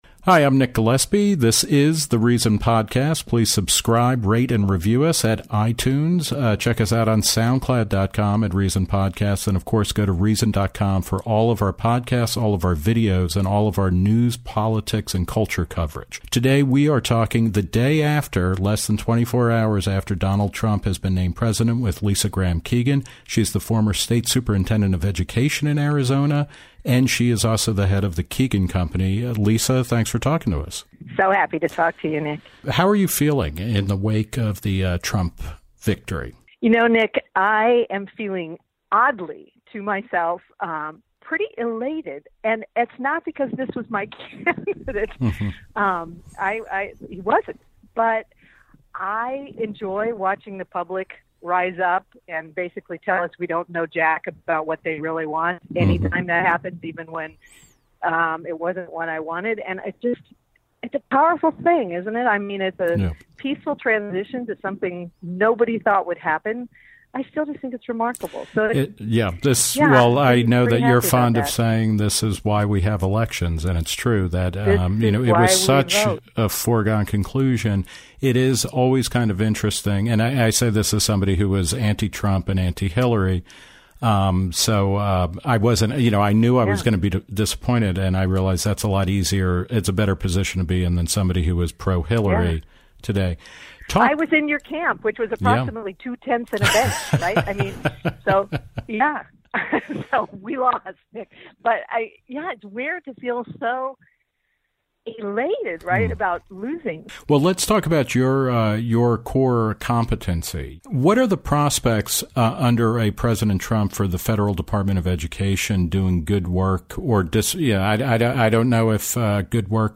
Nick Gillespie caught up with Keegan for an interview yesterday afternoon.